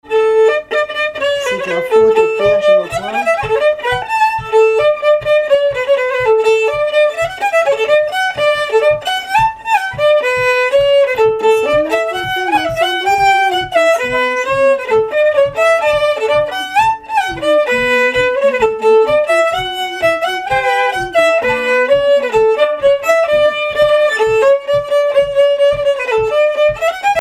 Saint-Pierre-et-Miquelon
danse : polka
Pièces instrumentales à plusieurs violons
Pièce musicale inédite